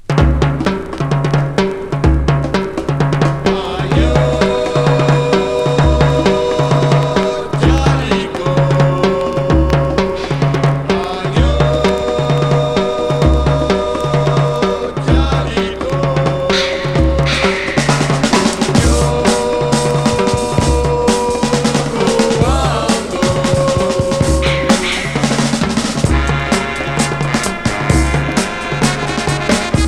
Groove percus Unique 45t retour à l'accueil